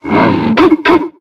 004a666b7b8f2b1119be5ca222f0c9be8b32a839 infinitefusion-e18 / Audio / SE / Cries / STOUTLAND.ogg infinitefusion d3662c3f10 update to latest 6.0 release 2023-11-12 21:45:07 -05:00 13 KiB Raw History